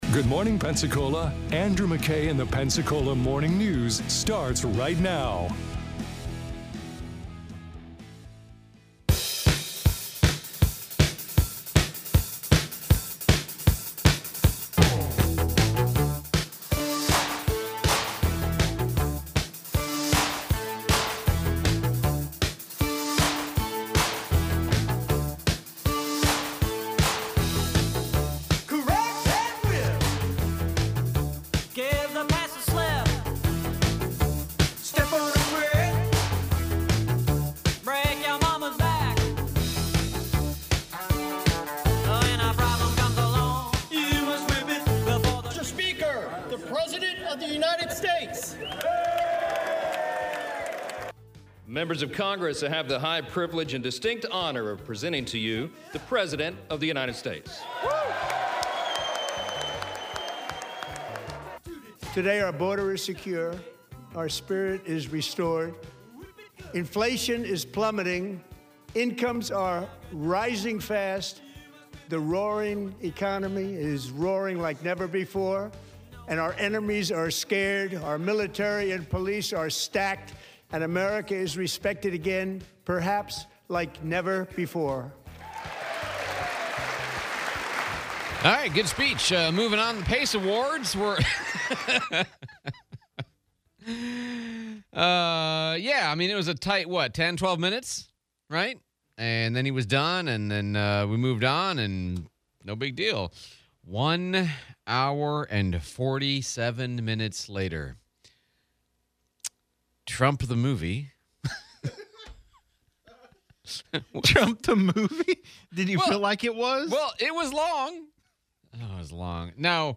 State of the Union Speech, replay of Escambia County Sheriff Chip Simmons